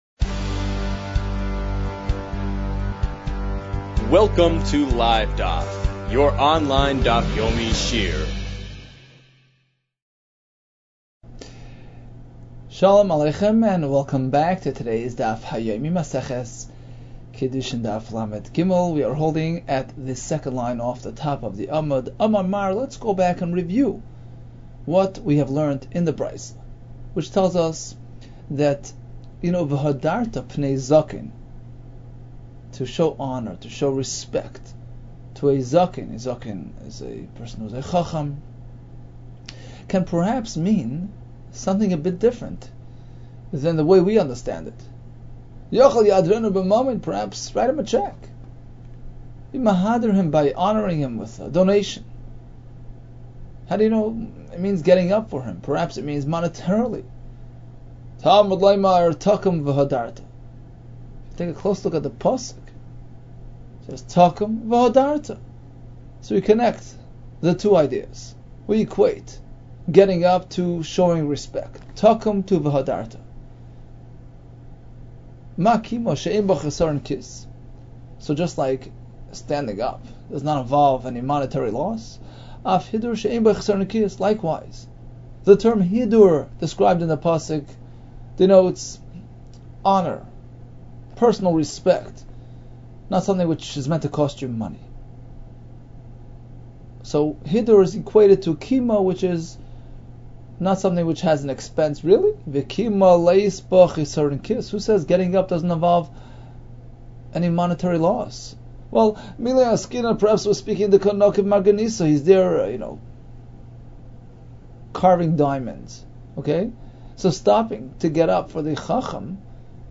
Kiddushin 32 - קידושין לב | Daf Yomi Online Shiur | Livedaf